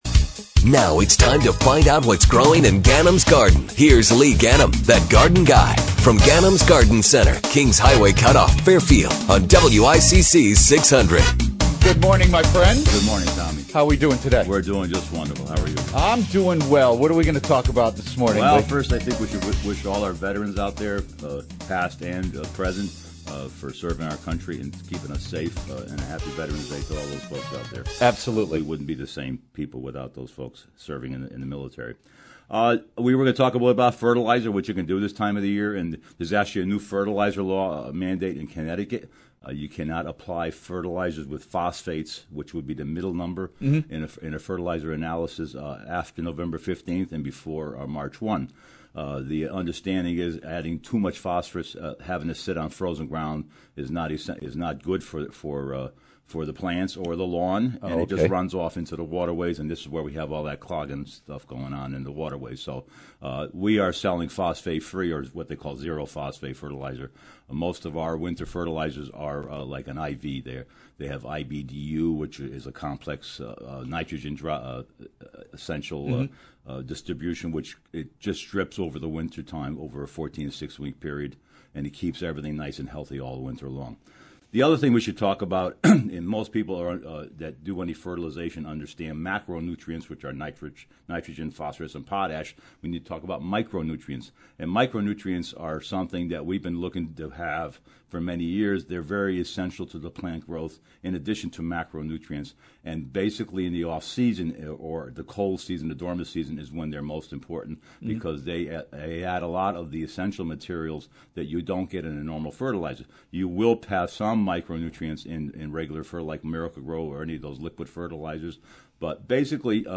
WICC-600 AM, November 10, 2013